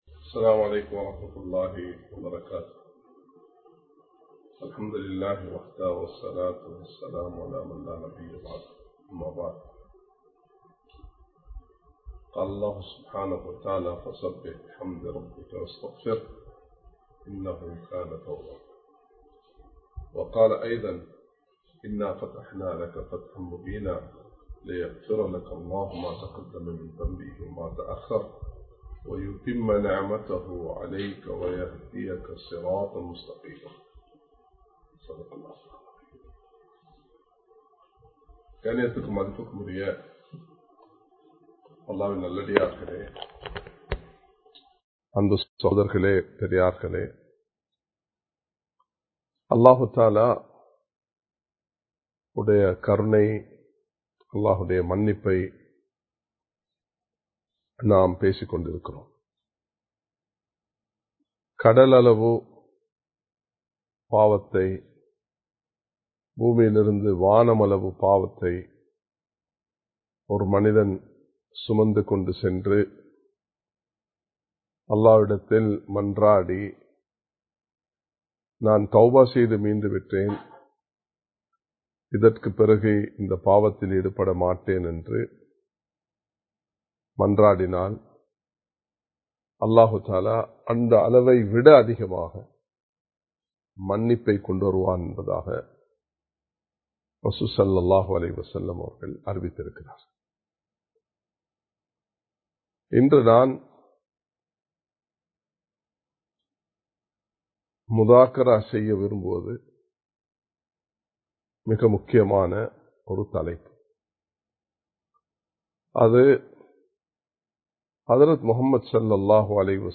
Live Stream